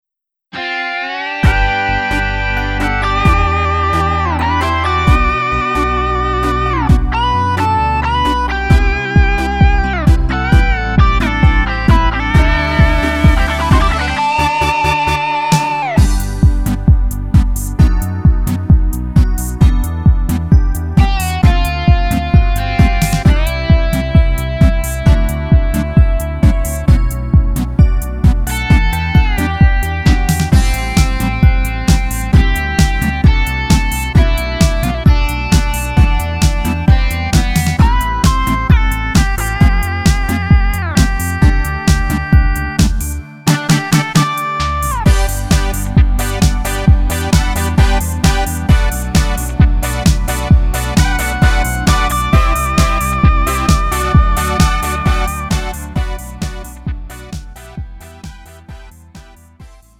음정 원키 2:34
장르 구분 Lite MR